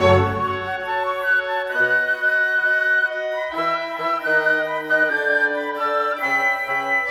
Rock-Pop 01 Orchestra 01.wav